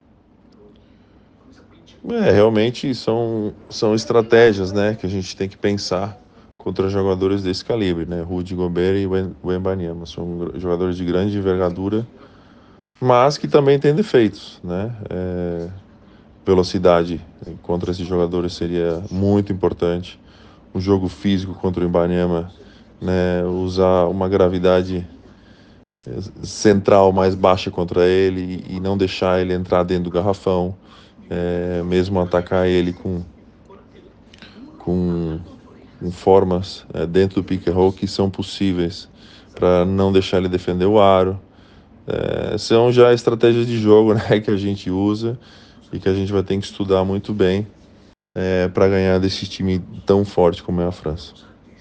Entrevista com Tiago Splitter – Auxiliar técnico da Seleção Brasileira de Basquete